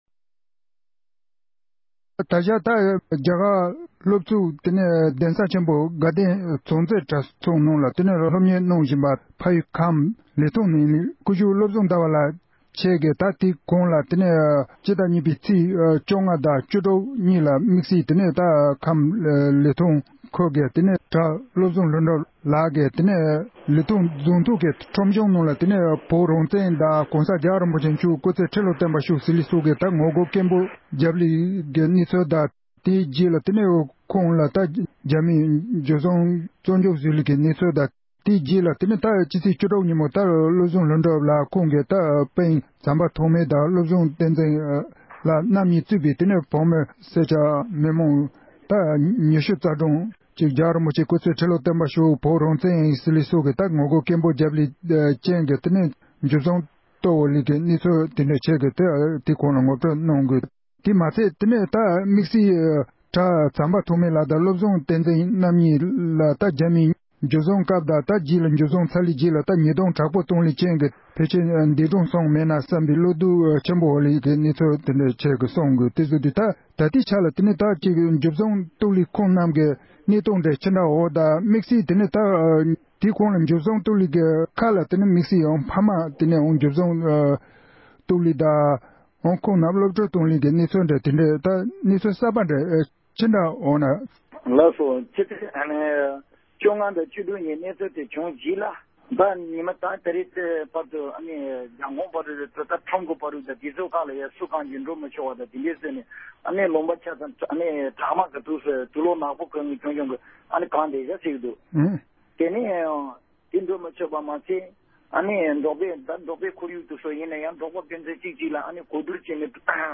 འབྲེལ་ཡོད་མི་སྣར་བཀའ་འདྲི་ཞུས་པ་ཞིག་གསན་རོགས་གནང་༎